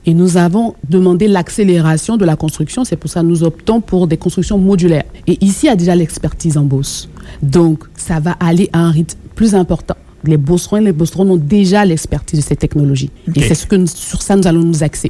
En entrevue à Radio Beauce, elle a souligné l’importance économique de la région, qu’elle décrit comme un véritable poumon pour le Québec.